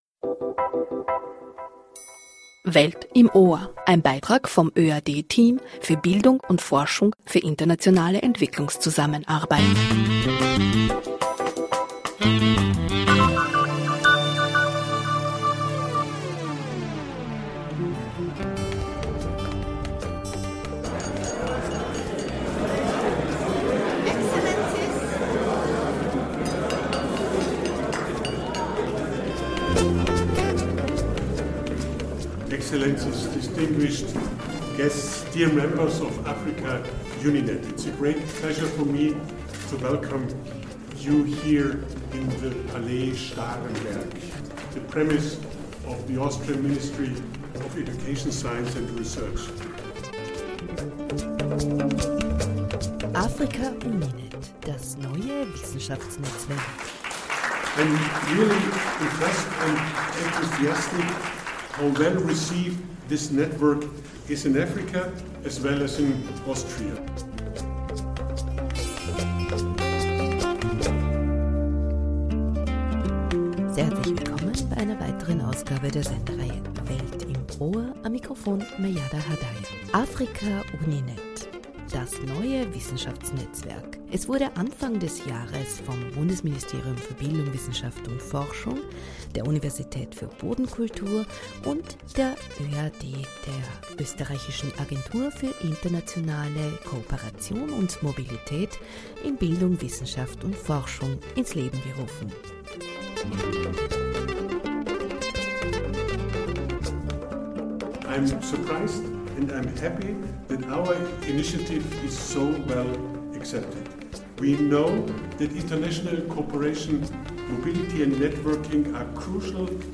"Africa-UniNet"– das neue Wissenschaftsnetzwerk – wurde ins Leben gerufen und wir waren bei der feierlichen Eröffnung dabei.